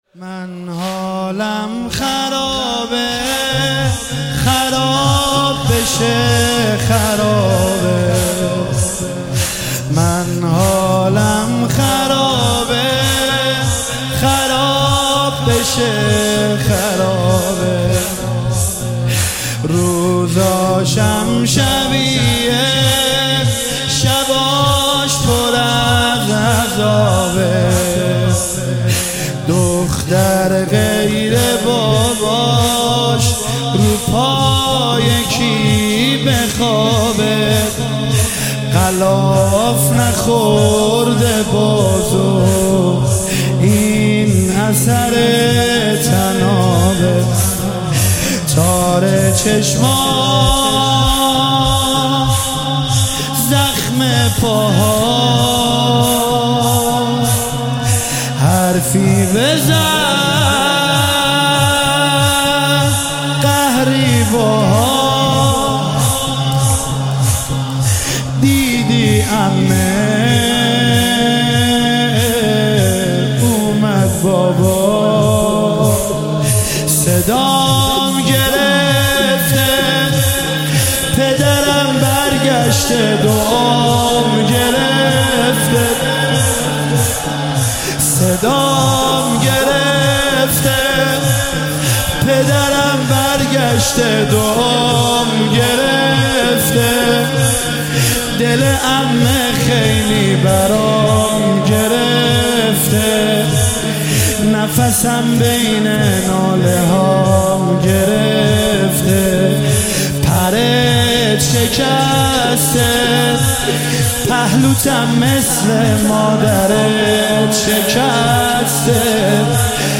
مداحی شب سوم محرم
با نوای دلنشین